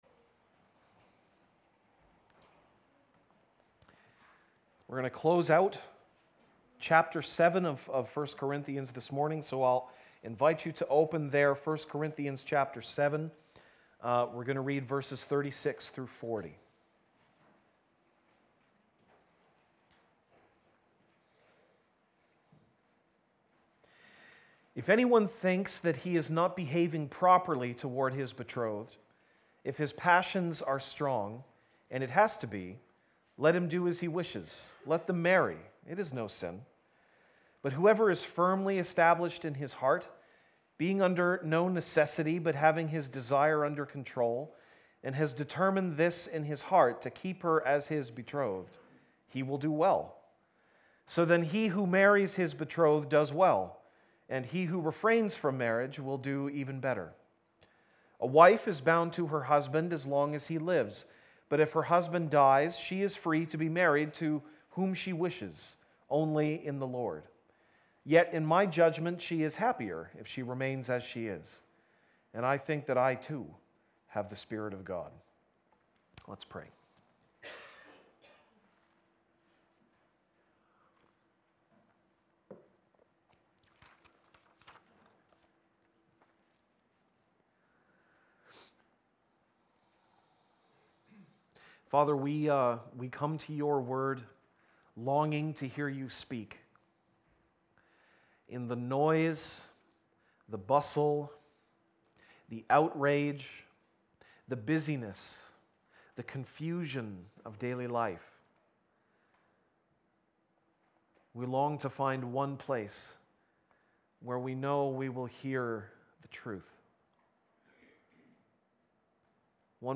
September 30, 2018 (Sunday Morning)